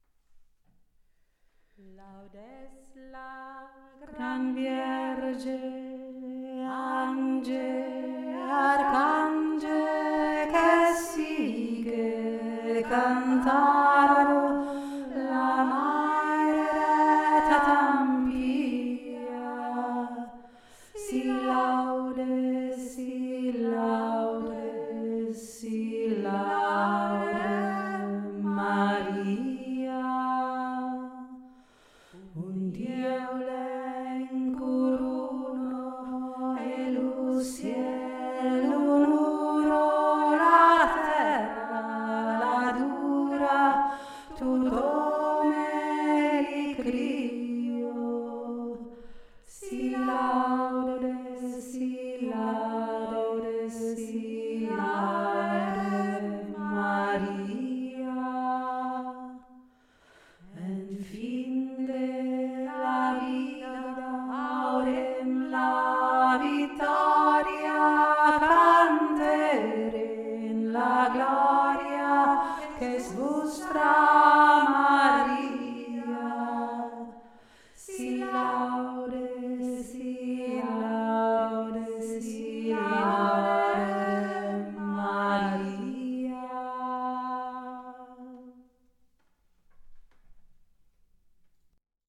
SI LAUDE MARIA - sardisches Marienlied
Si Laude tiefe Stimme
laudes-la-gran-vierge-tiefe-stimme.mp3